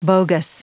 Prononciation du mot bogus en anglais (fichier audio)
Prononciation du mot : bogus